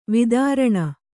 ♪ vidāraṇa